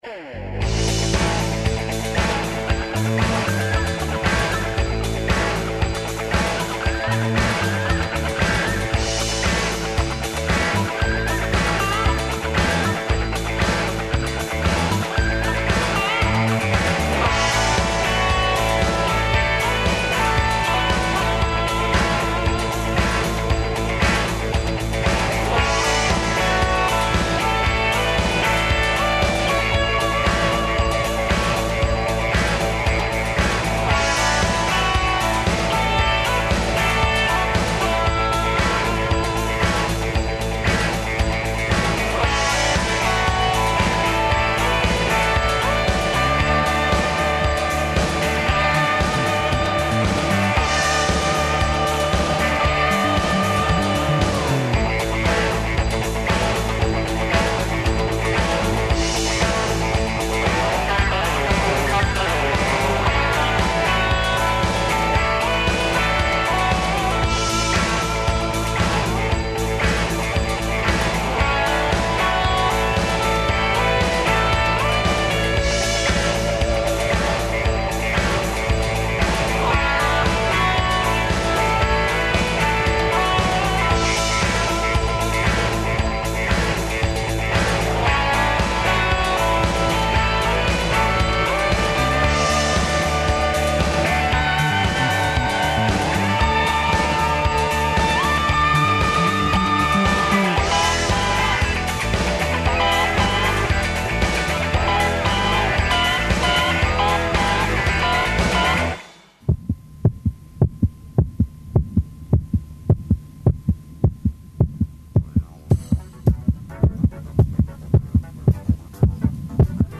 Кроз различите музичке жанрове - од народне до класичне музике, чућете најпознатије композиције - дуете.
Између музичких нумера сазнаћете и занимљиве приче о извођачима и настанку дуета.